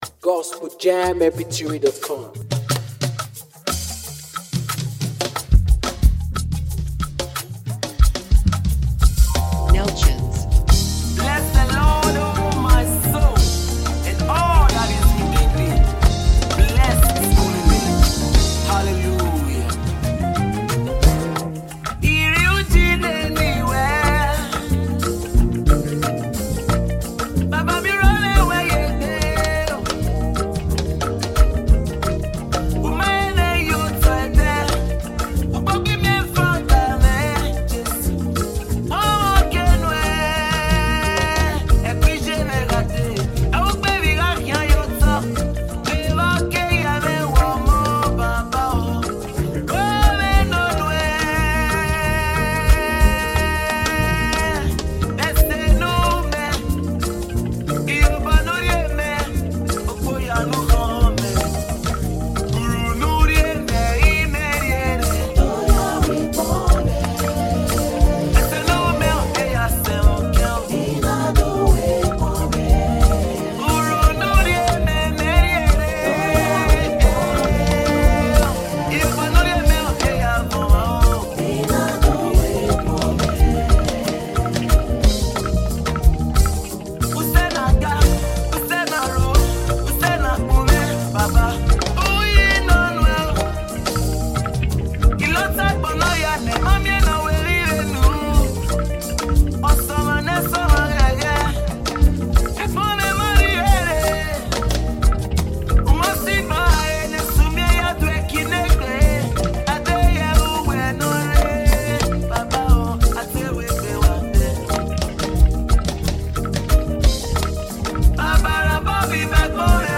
NUMBER 1 AFRICA GOSPEL PROMOTING MEDIA